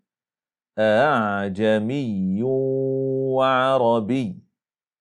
Det innebär att vi uttalar detn andra hamzahn mellan en hamzah och en alif, så att det varken är ett rent hamzah eller ett rent alif.
Vi underlättade den andra hamzahn vilket blev: